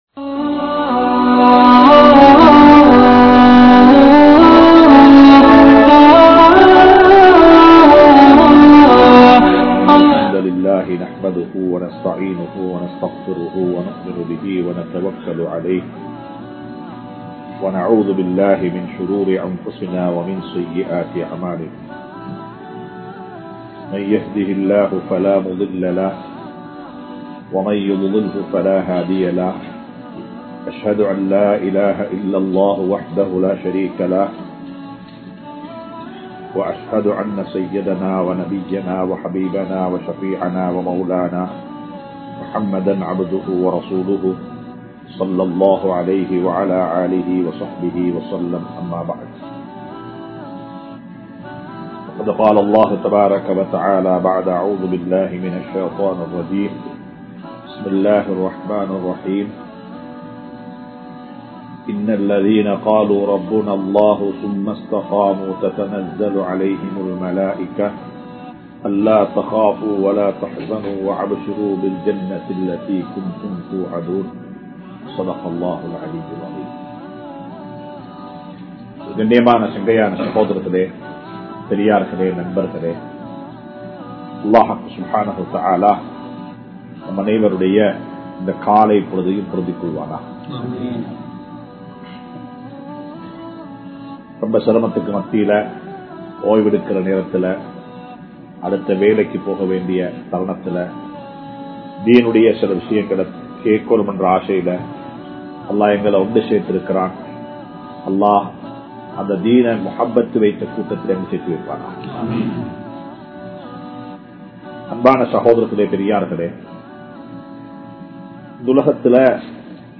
Maranaththitku Mun Emaanudaiya Ulaippu (மரணத்திற்கு முன் ஈமானுடைய உழைப்பு) | Audio Bayans | All Ceylon Muslim Youth Community | Addalaichenai